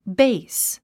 発音
béis　ベイス